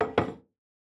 Food & Drink, Tableware, Emply Plate, Place On Table 03 SND109093.wav